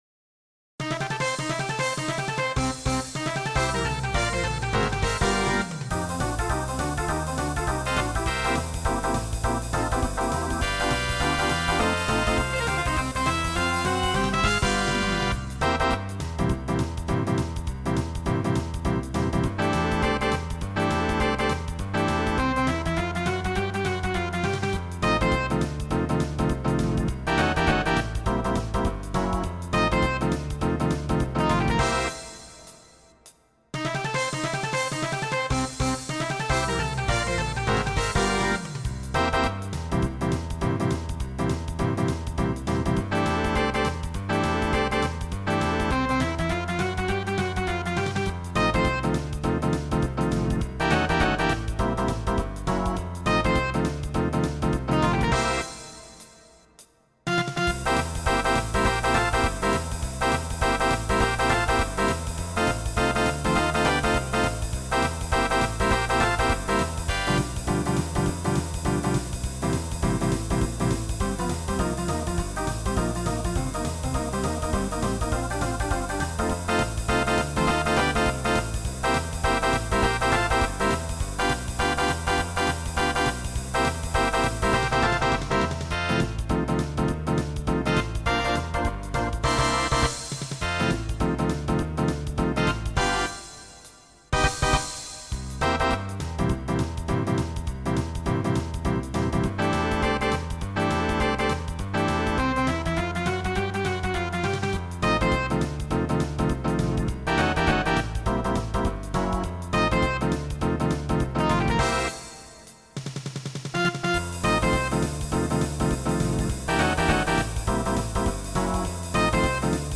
Voicing SATB Instrumental combo Genre Swing/Jazz